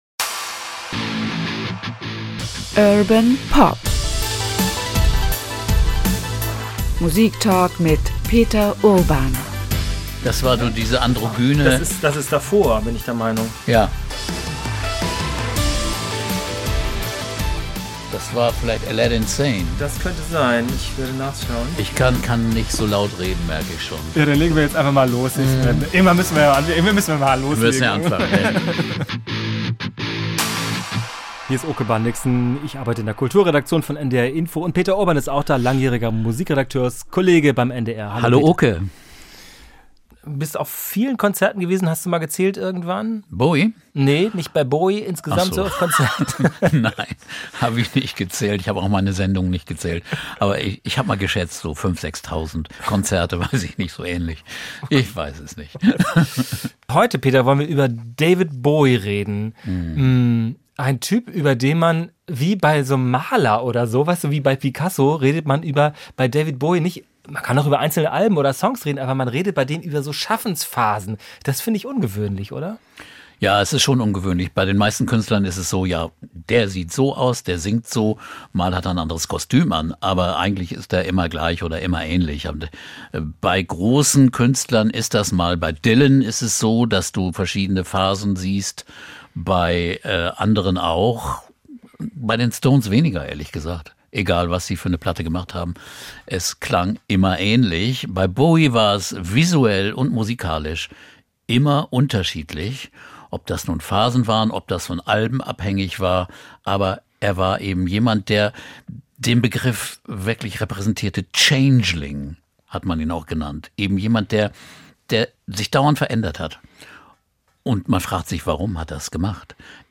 David Bowie ~ Urban Pop - Musiktalk mit Peter Urban Podcast